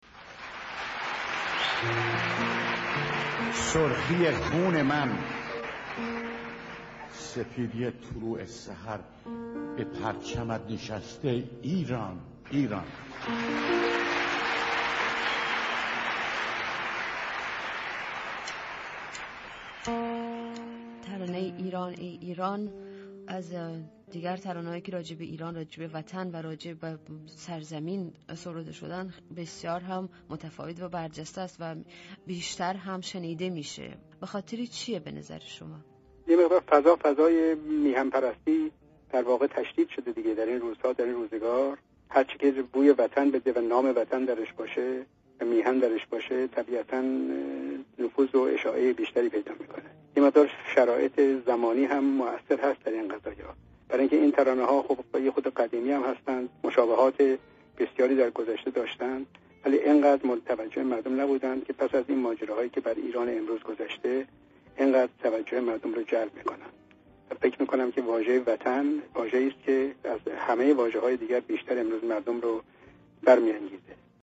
Radio Zamaneh Interview